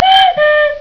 snd_30007_Couckoo.wav